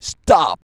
STOP.wav